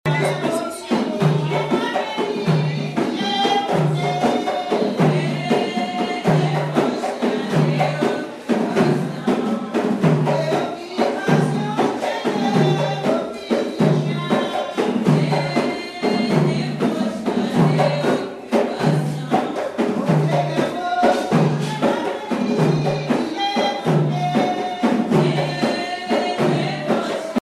groupe folklorique Les Ansyens de Kourou
danse : grajé (créole)
Pièce musicale inédite